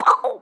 Chicken_6.wav